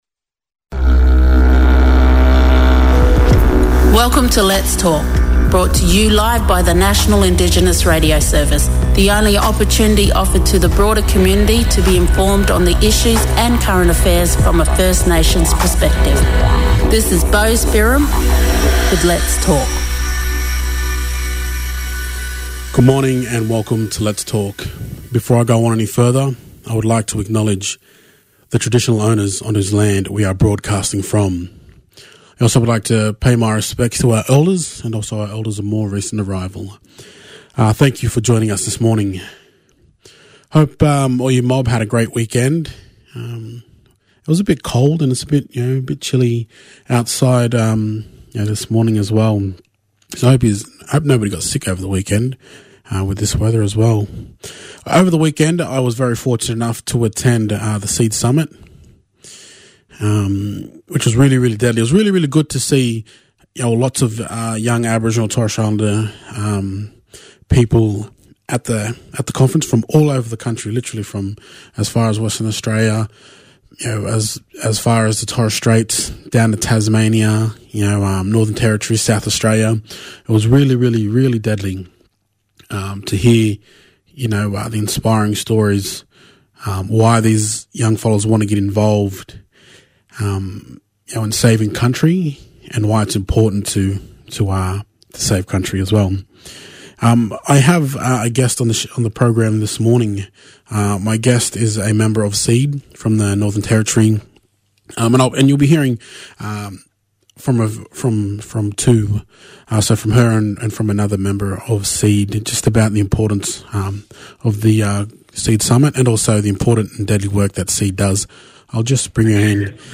spoke with some of the mob from the seed summit held in Brisbane this year